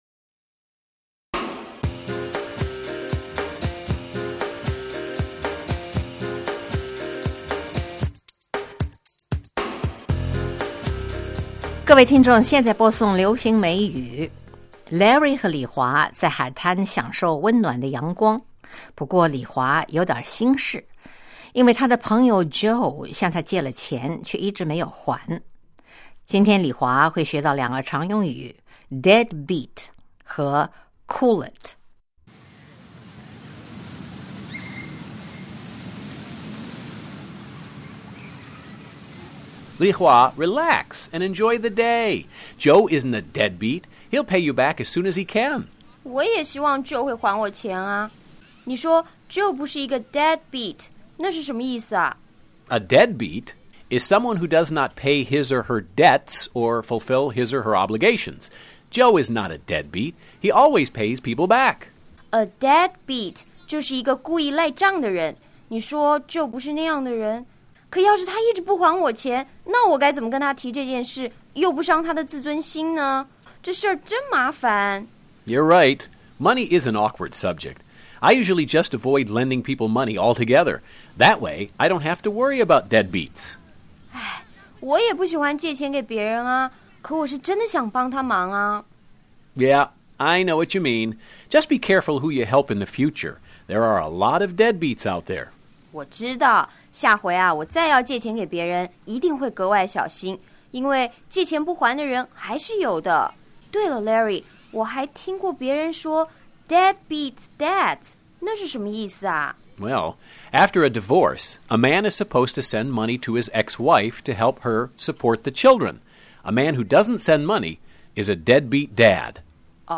(Ambience sound on the beach)